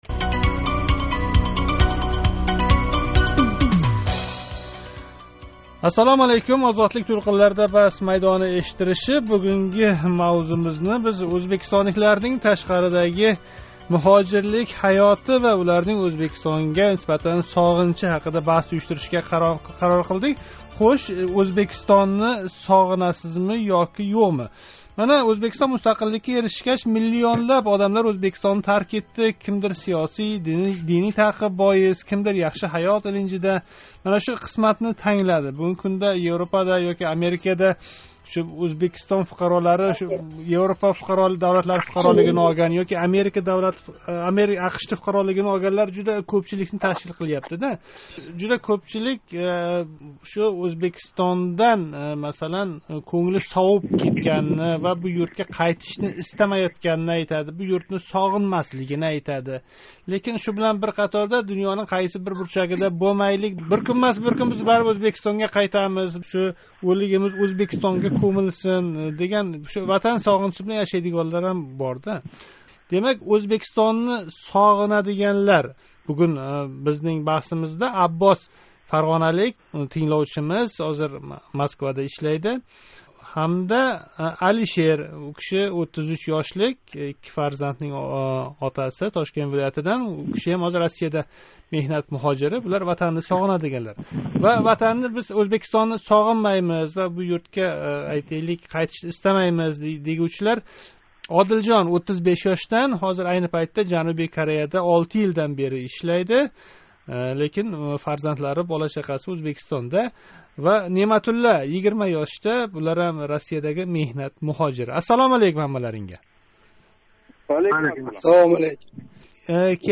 Озодликнинг баҳс майдонига турли сабаблар билан Ўзбекистонни тарк этган муҳожирларни таклиф этдик. Унда бугунги Ўзбекистон табиатини, одамларини, муҳитини соғинаётган ёки бу муҳитга умуман қайтишни истамаётган муштарийлар мунозара юритди.